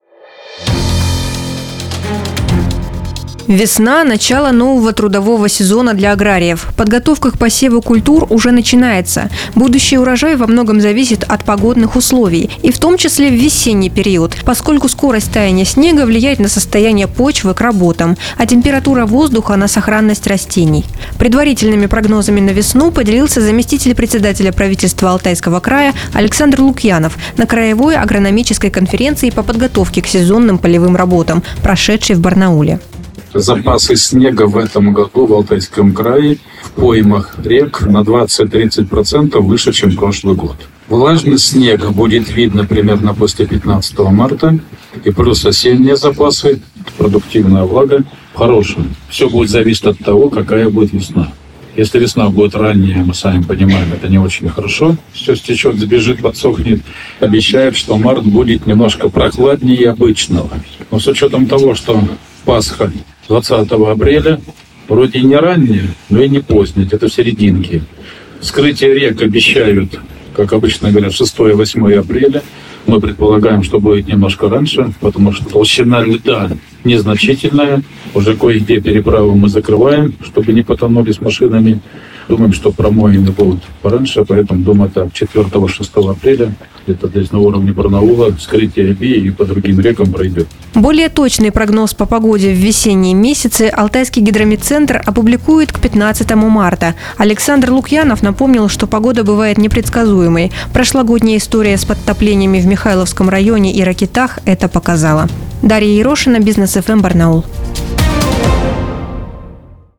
Предварительными прогнозами на весну с радиостанцией Business FM (Бизнес ФМ) Барнаул поделился заместитель председателя правительства Алтайского края Александр Лукьянов на краевой агрономической конференции по подготовке к сезонным полевым работам, прошедшей в Барнауле.
Сюжет на Business FM (Бизнес ФМ) Барнаул